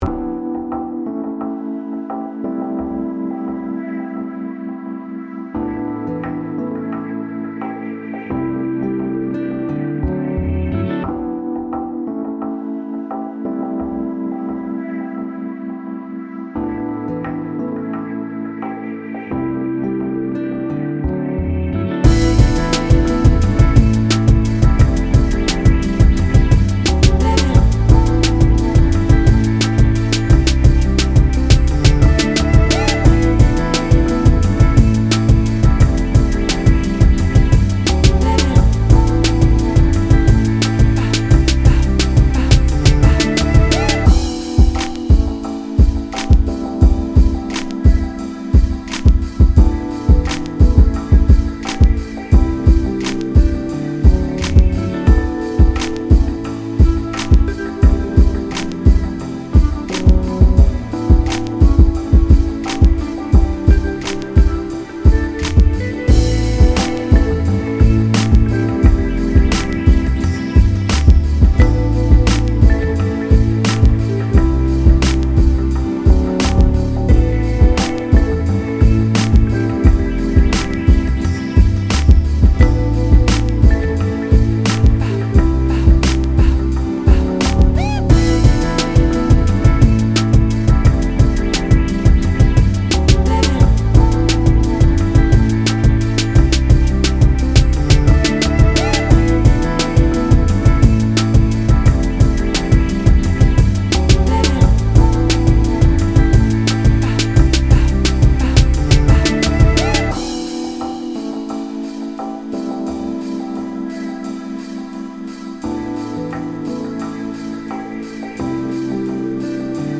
bounce , hiphop
Instrumental